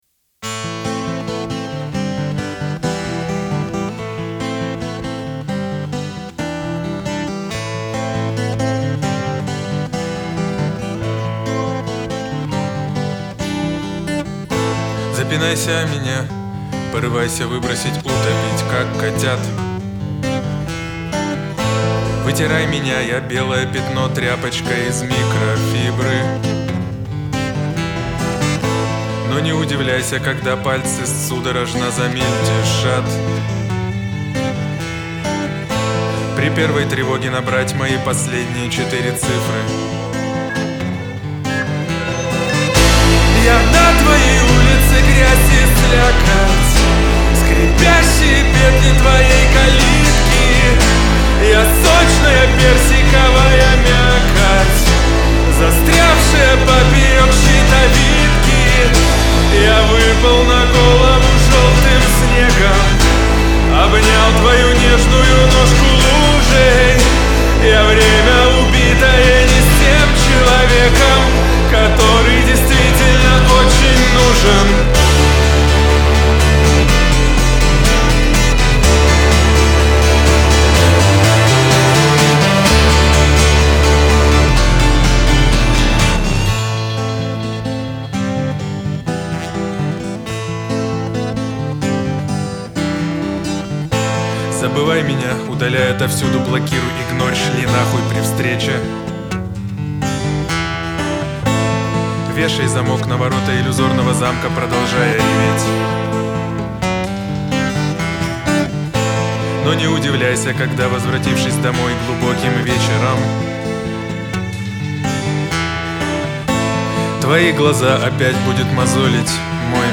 это мощный пример русского рока